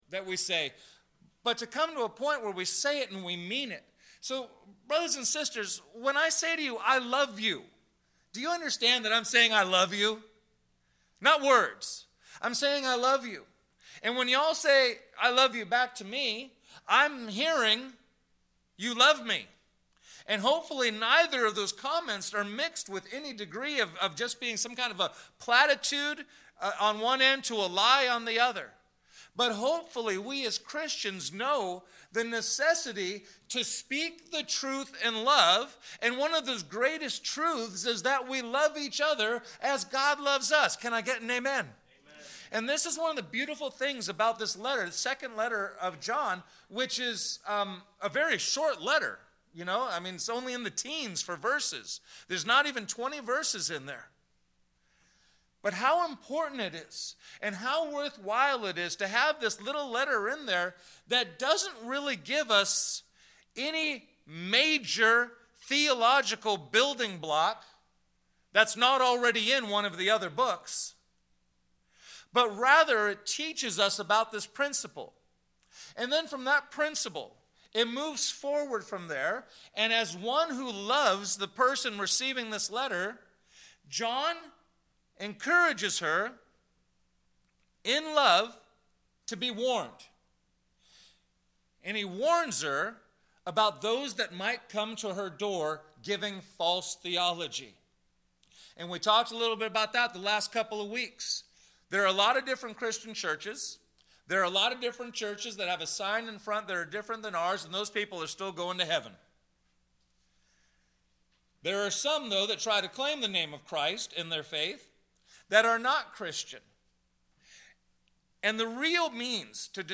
Sermons - First Baptist Church Solvang